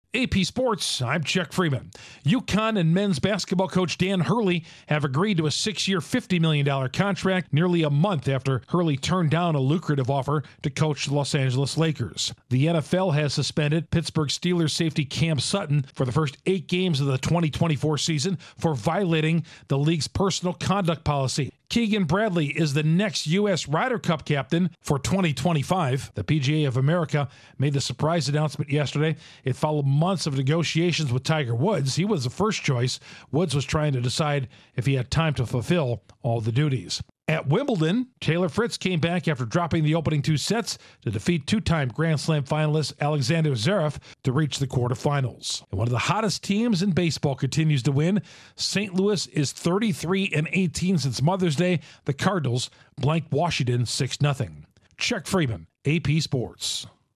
UConn agrees to a long term contract with coach Dan Hurley, Keegan Bradley named Ryder Cup captain, Day 8 at Wimbledon, and the Cardinals are on a roll. Correspondent